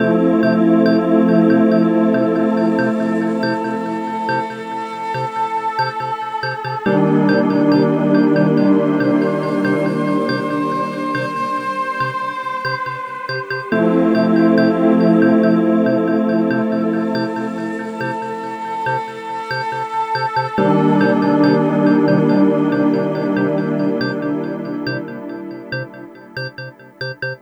Mellow Mood 02.wav